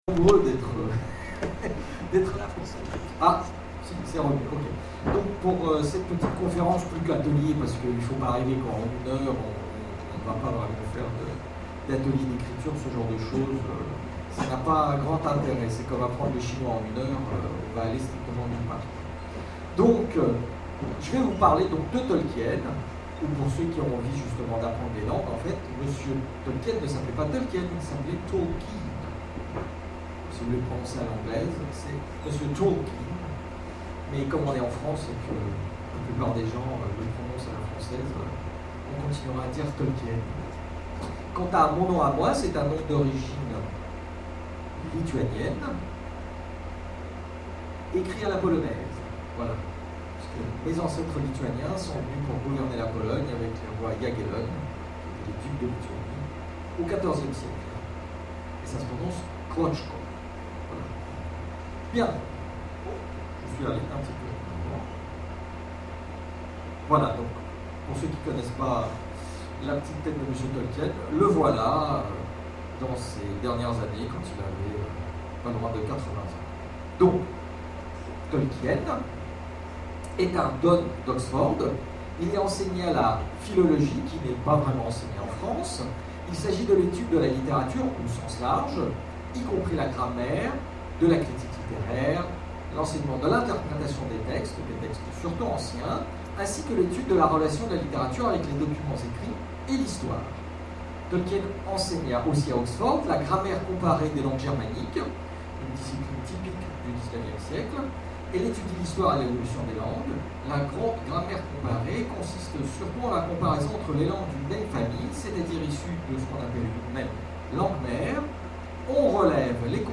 Imaginales 2013 : Conférence L'elfique sans peine